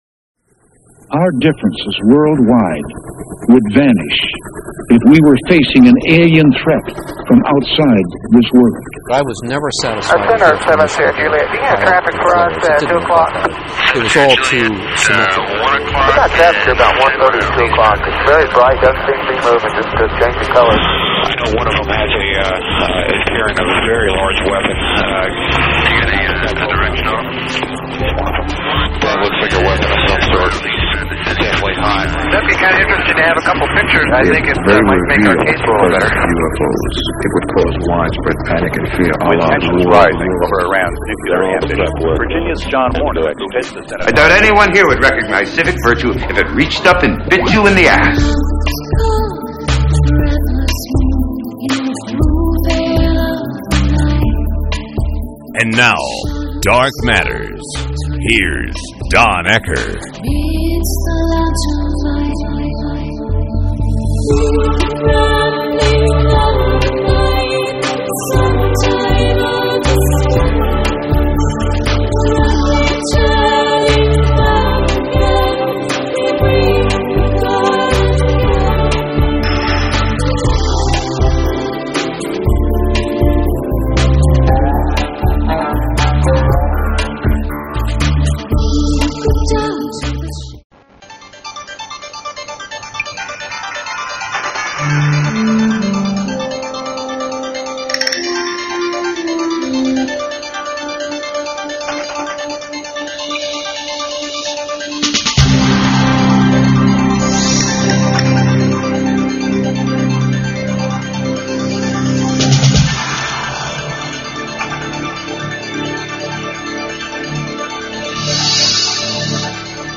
(Oh, I should mention this show had more music than any before this or even after.) https